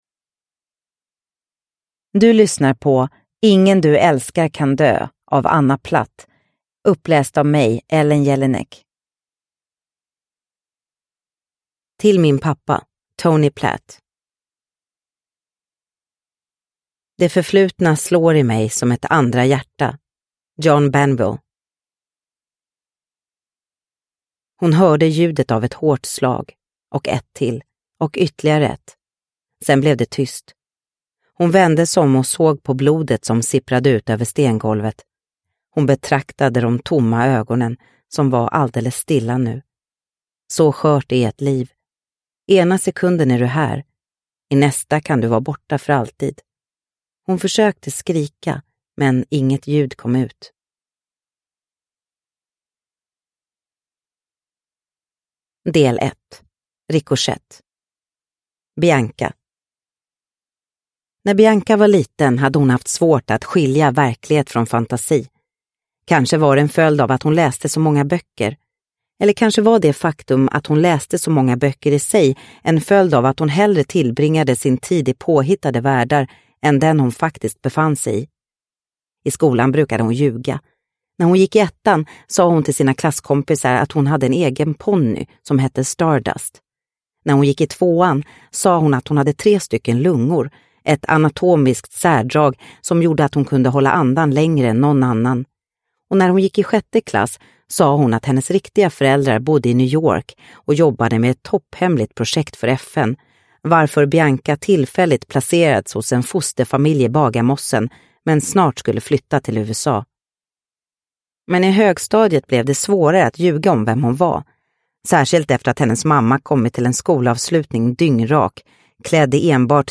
Ingen du älskar kan dö – Ljudbok – Laddas ner